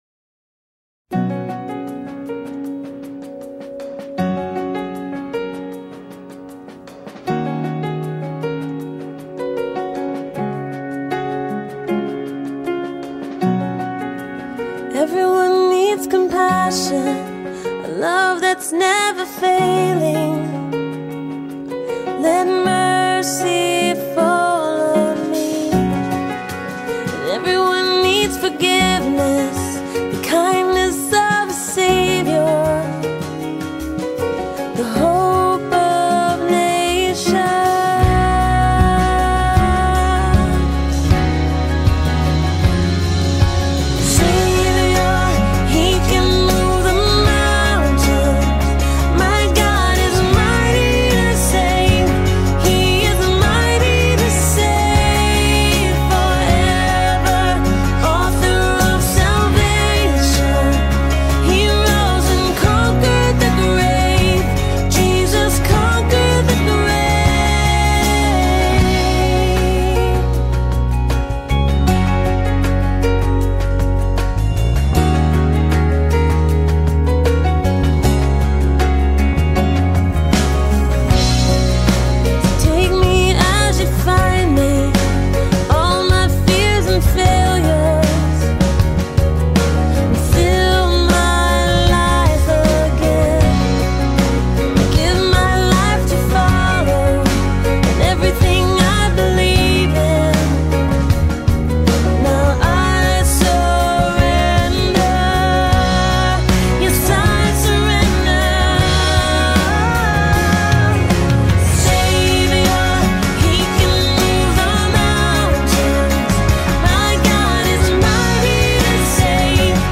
Our service begins with the singing one of two hymns, the more contemporary song being found here and the traditional hymn being here.
Welcome to this time of worship.